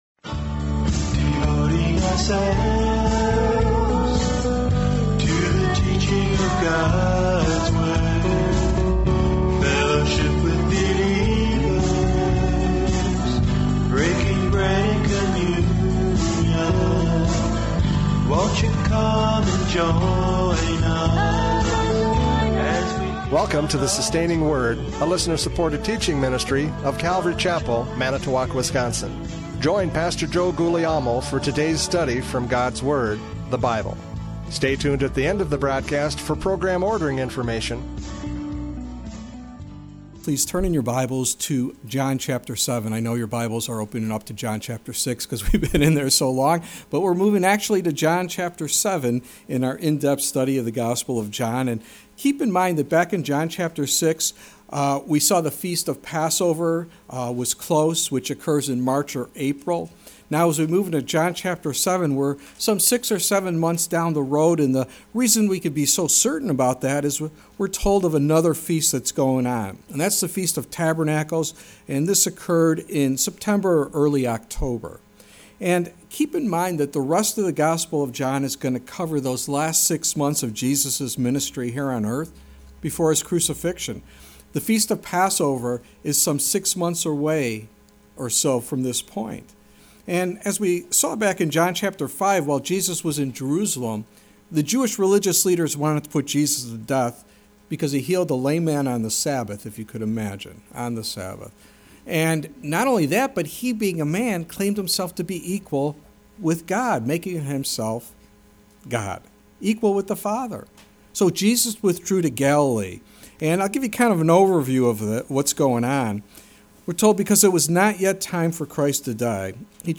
John 7:1-13 Service Type: Radio Programs « John 6:60-71 True and False Disciples!